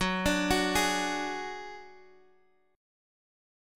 F#sus2#5 Chord